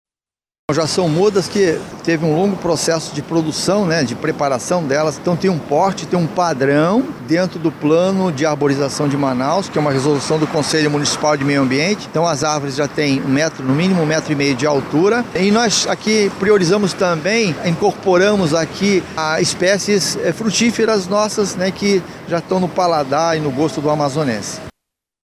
Sonora-2-Antonio-Stroski-–-secretario-da-Semmas.mp3